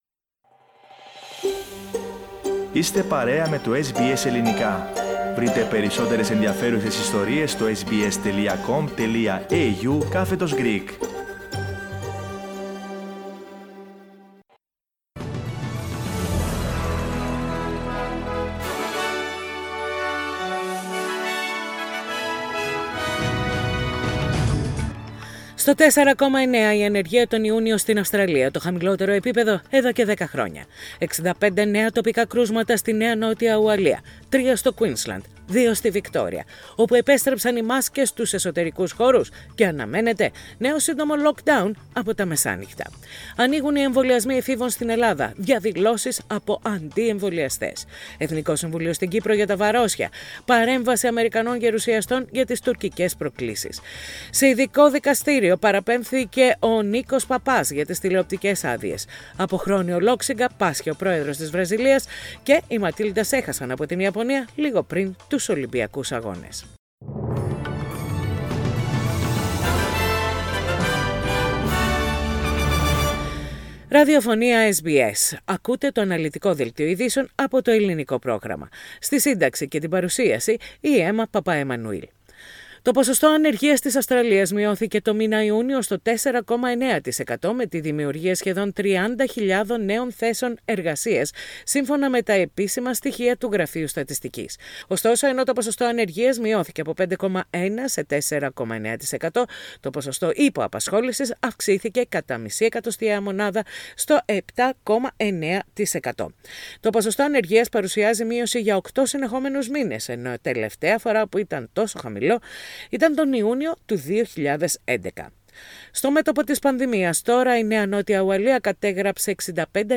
News in Greek - Thursday 15.7.21